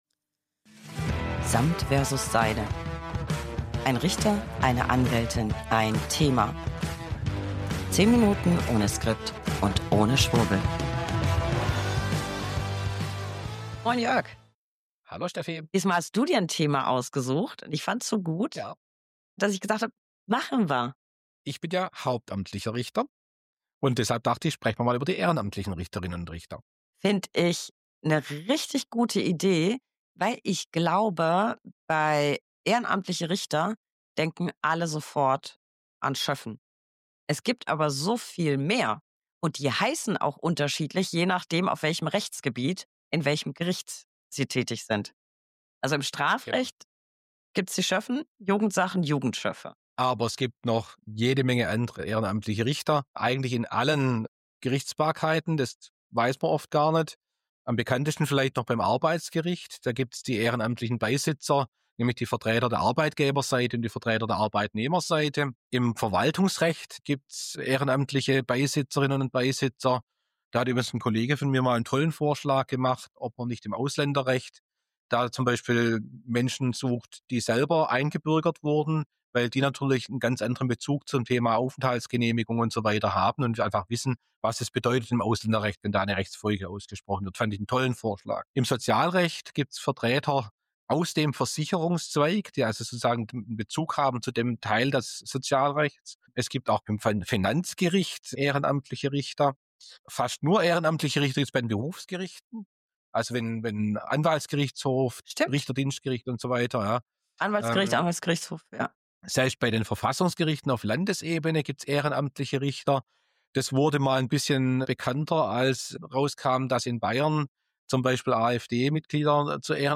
1 Anwältin + 1 Richter + 1 Thema. 10 Minuten ohne Skript und ohne Schwurbel.